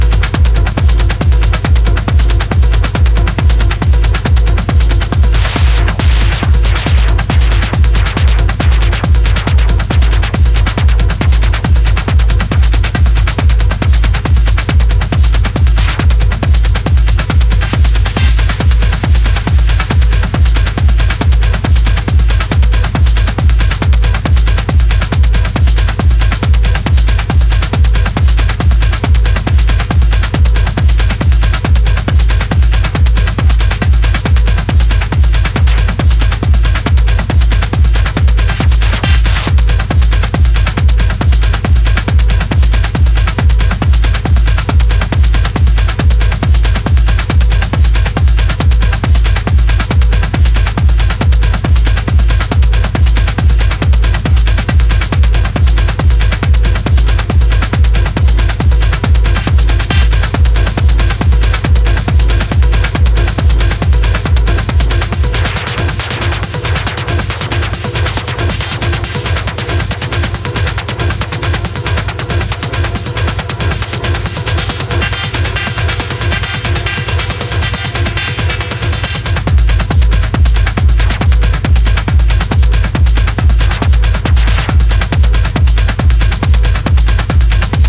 an exhilarating dive into the heart of underground techno.
a pulsating journey